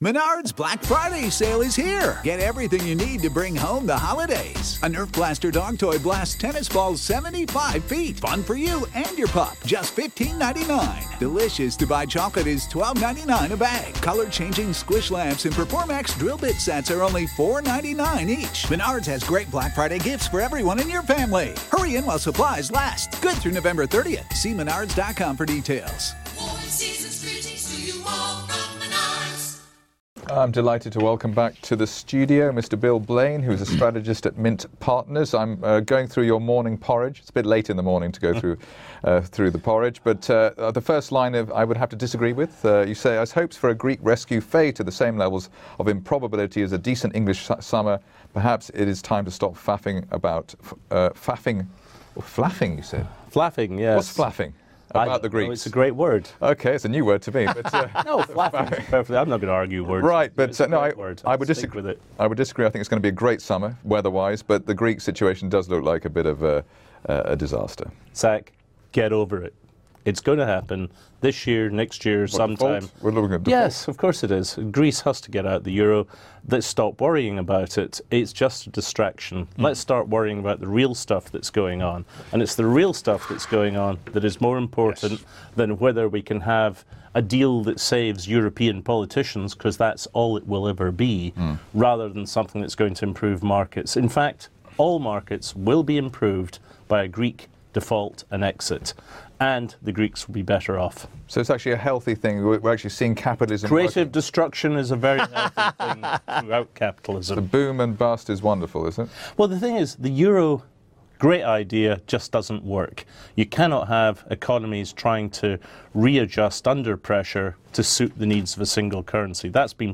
As we anticipate normalisation of the markets once rate hikes set in, the Greek situation is hiding the real situation in the global markets. Global macro-economic discussion